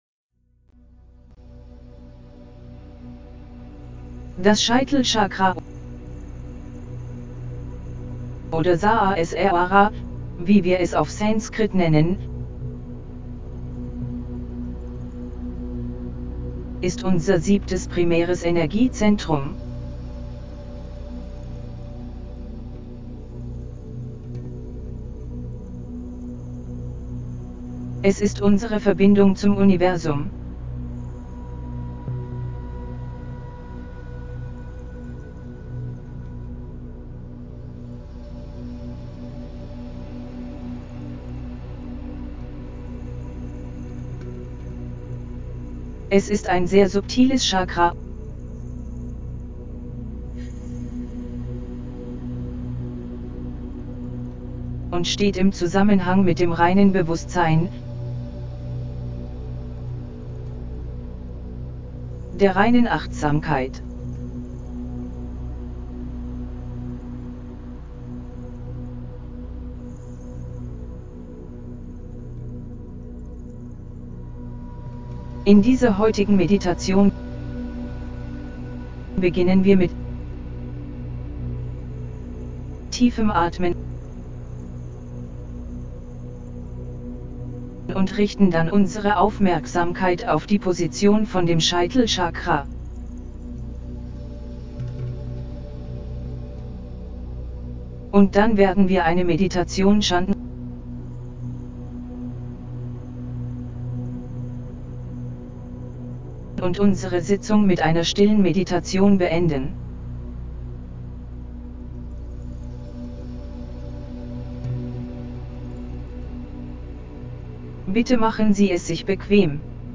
7CrownChakraGuidedMeditationDE.mp3